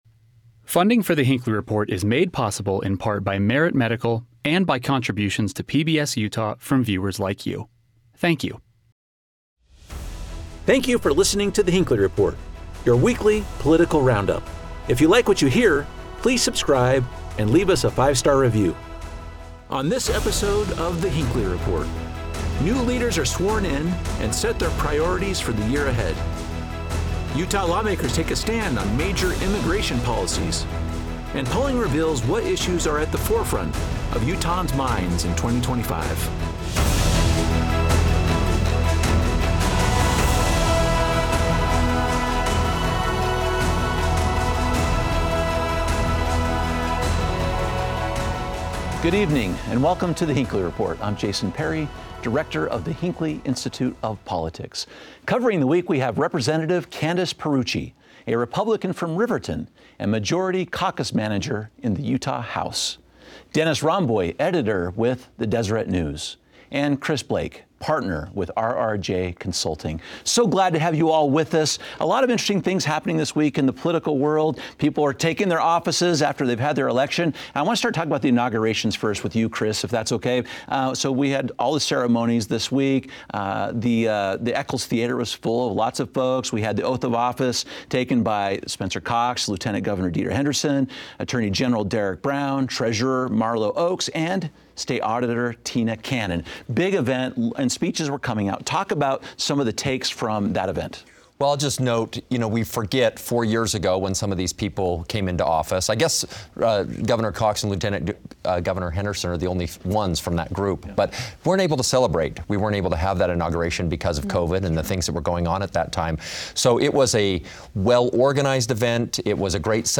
Our panel examines the tone of their speeches, and what it signals about their next four years in office.